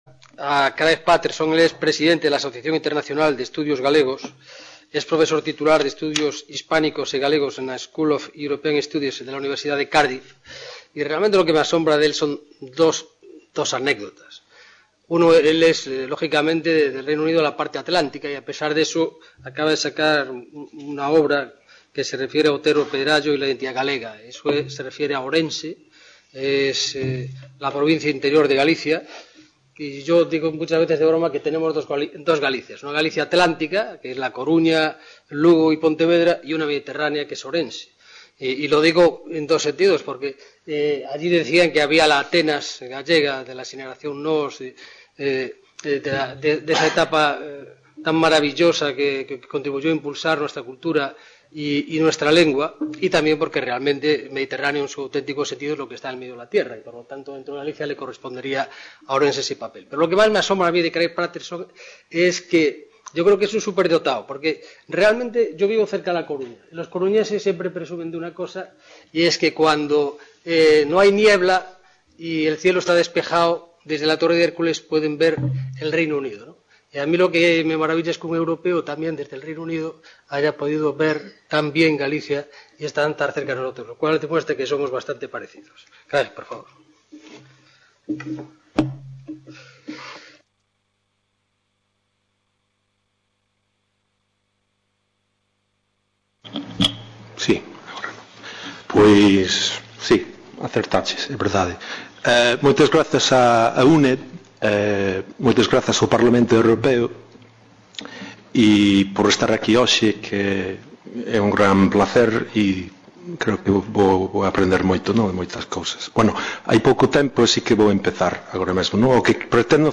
Mesa redonda: Literatura gallega y Europa
MODERADOR: Antolín Sánchez Presedo, Diputado del Parlamento Europeo
Reunion, debate, coloquio...